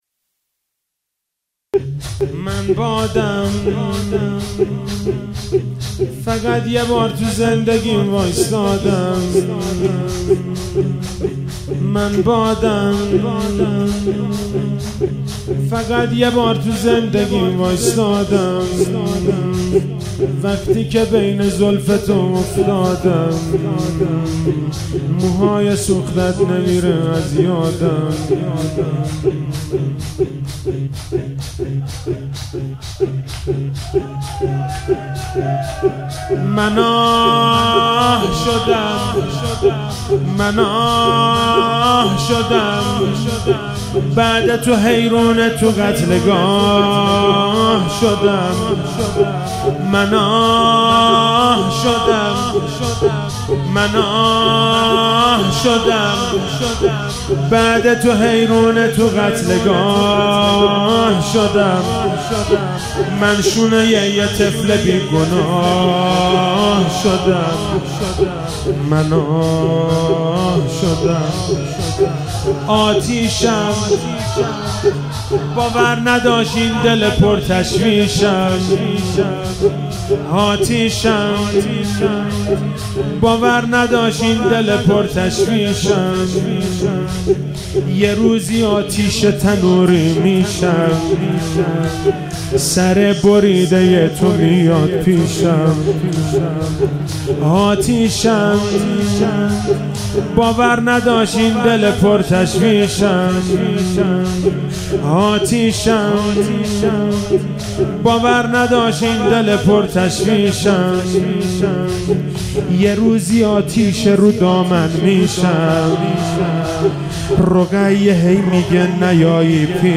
محرم99